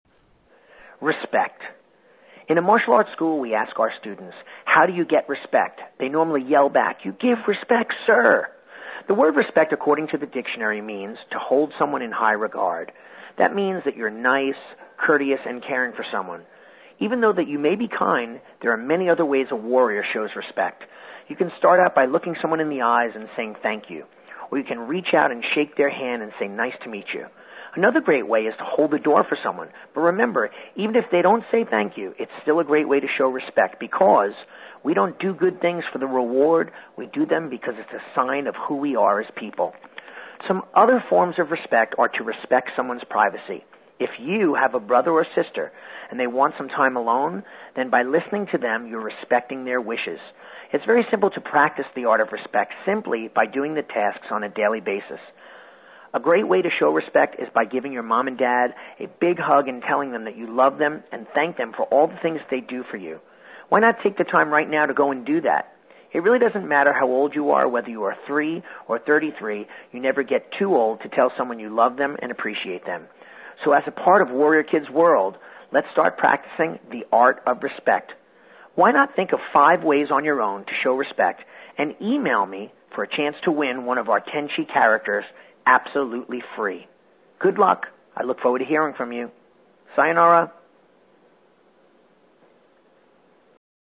When you listen to the audio stories on this page, you’ll hear the tales told with energy, excitement, and heart.